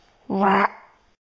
描述：这是我无缘无故地发出随机噪音。
Tag: 生物 动物 咆哮 幻想 怪兽 低吼